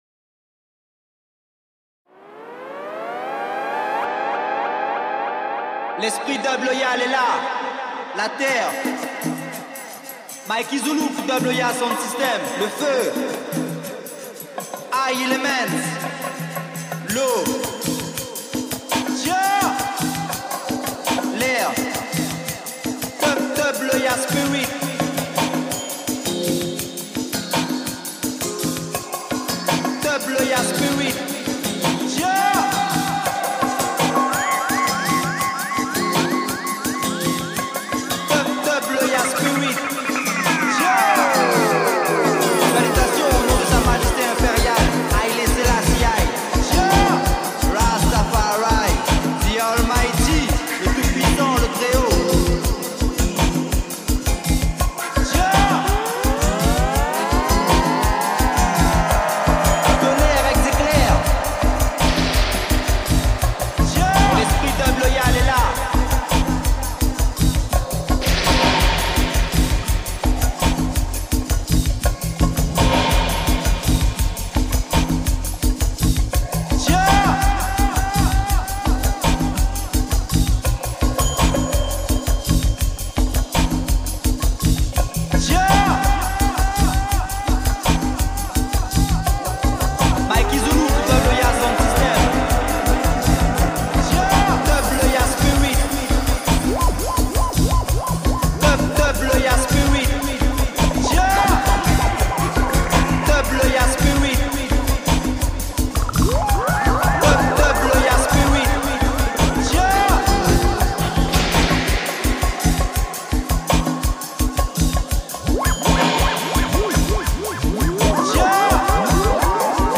DUB WORK >>> REGGAE